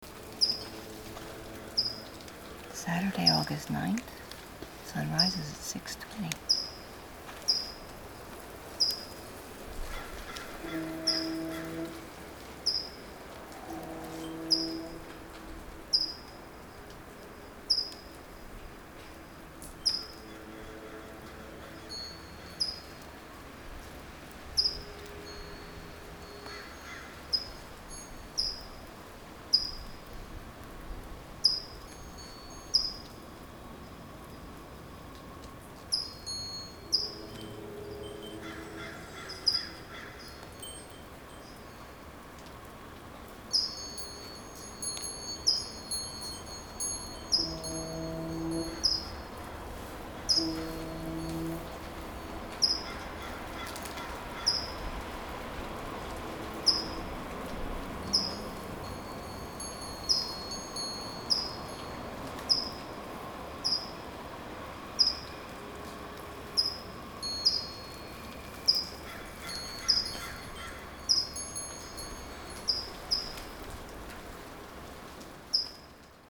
Dripping and foghorns. That’s not rain. It’s dripping.